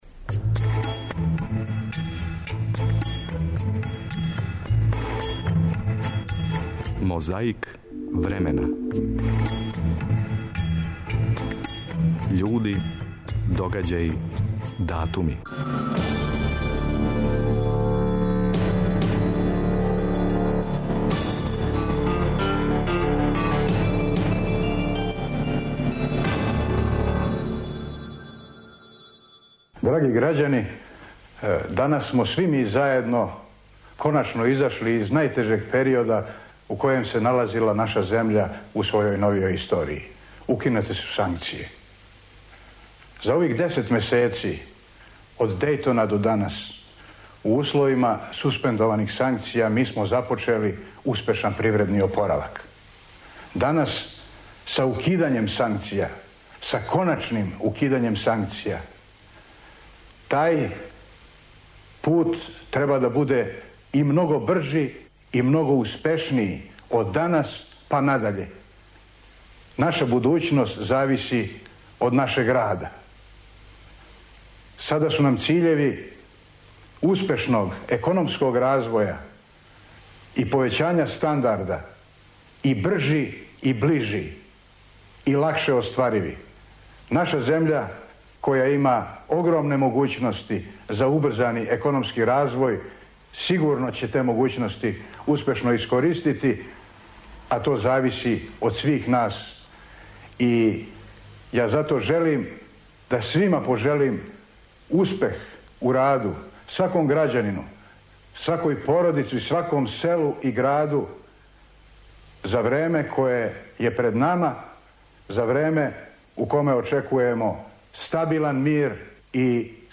И опет 3. октобар, али 1991., и звучни запис са конференције за новинаре Српске народне обнове на којој је говорио председник странке Мирко Јовић.
Подсећа на прошлост (културну, историјску, политичку, спортску и сваку другу) уз помоћ материјала из Тонског архива, Документације и библиотеке Радио Београда.